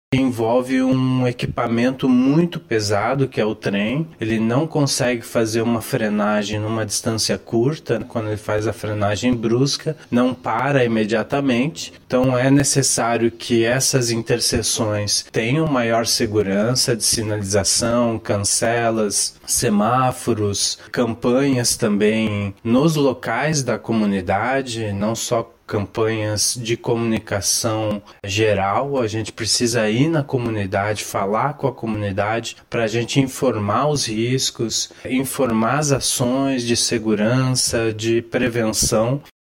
O que diz o especialista em Trânsito